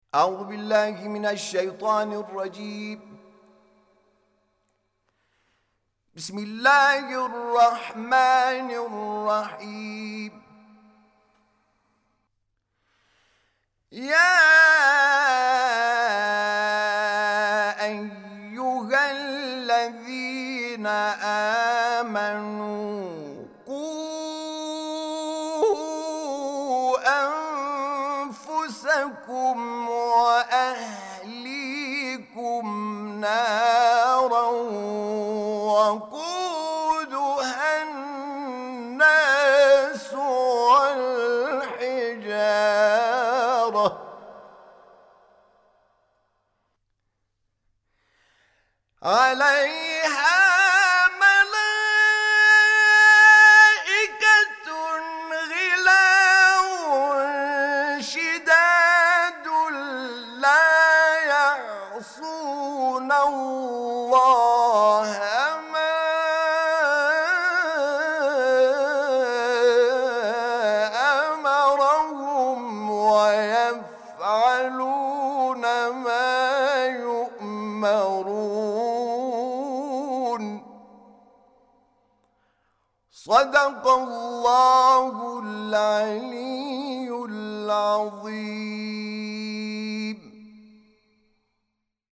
تلاوت آیه 6 سوره مبارکه تحریم توسط حامد شاکرنژاد